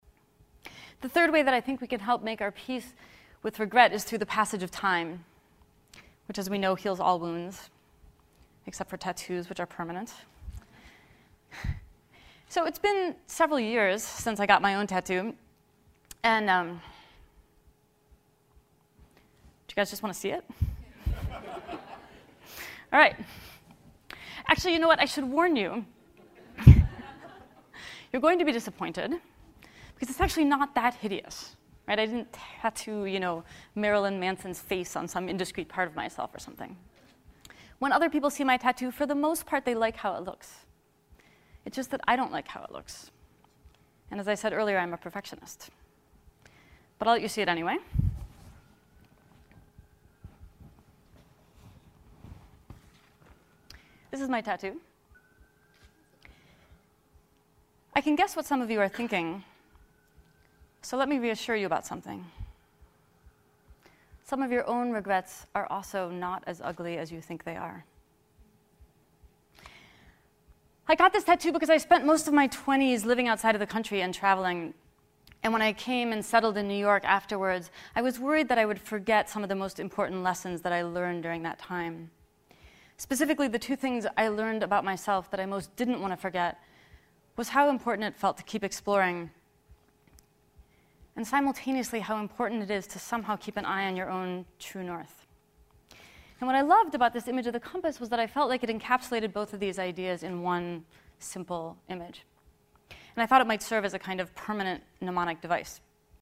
TED演讲：不要一悔再悔(12) 听力文件下载—在线英语听力室